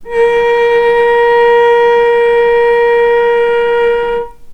healing-soundscapes/Sound Banks/HSS_OP_Pack/Strings/cello/ord/vc-A#4-mf.AIF at 48f255e0b41e8171d9280be2389d1ef0a439d660
vc-A#4-mf.AIF